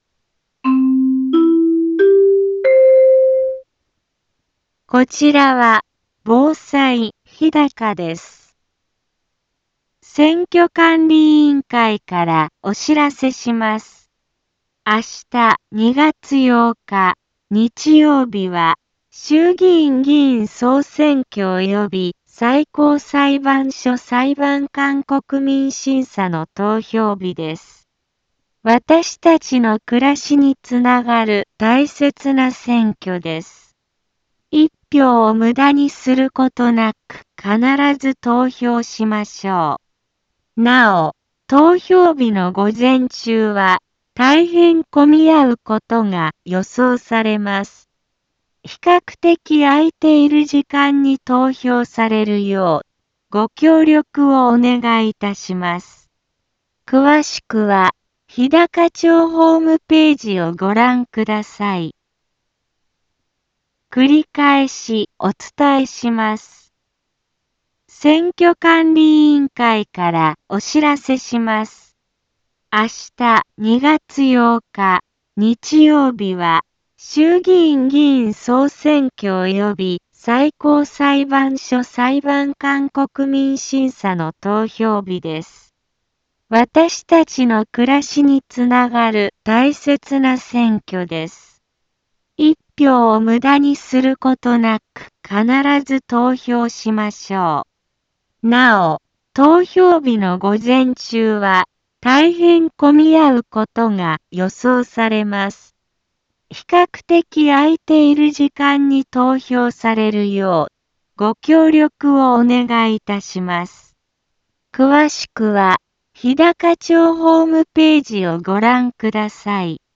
Back Home 一般放送情報 音声放送 再生 一般放送情報 登録日時：2026-02-07 10:04:42 タイトル：衆議院議員総選挙投票棄権防止の呼びかけ インフォメーション： こちらは、防災日高です。